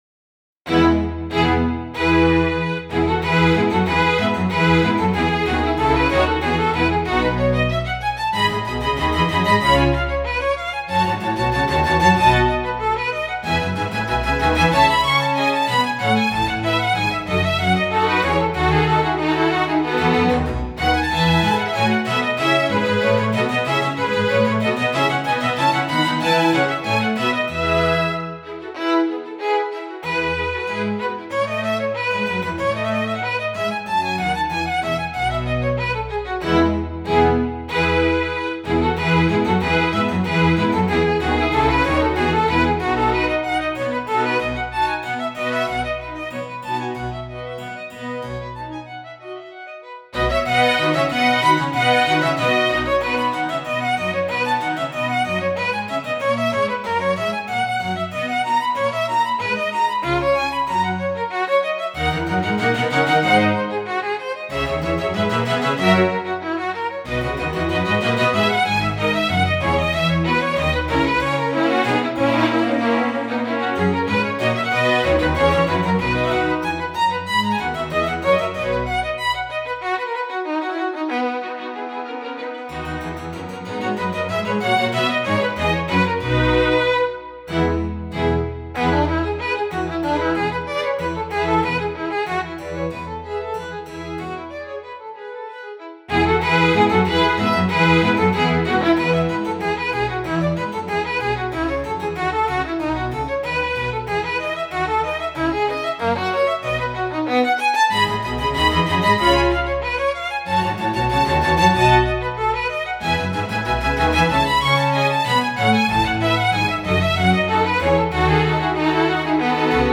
all created with samples
Most of the pieces are played between 2002 and 2014 and also mixed as it was normal at that time...